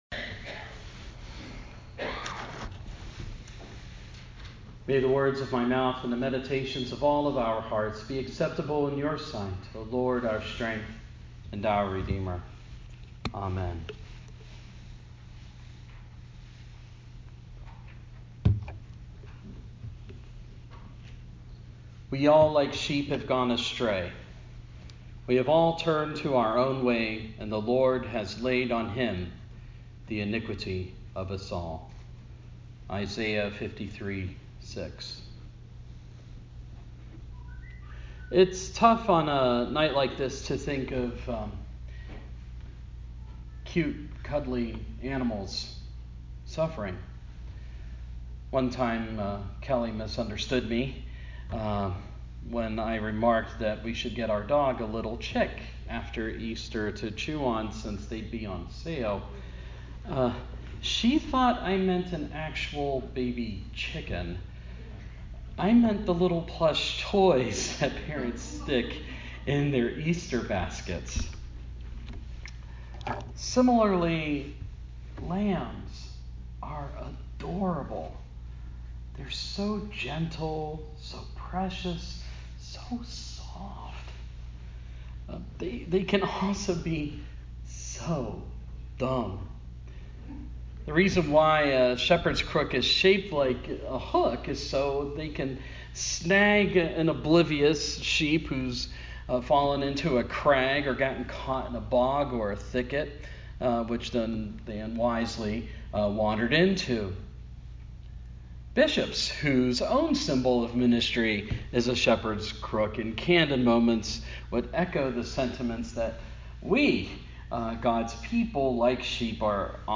Sharing Holy Week services this week with our friends at Calvary United Methodist is such a blessing! At their church on Good Friday, we considered what it means to be stray sheep and to have the Lamb of God take the sins of the world upon himself.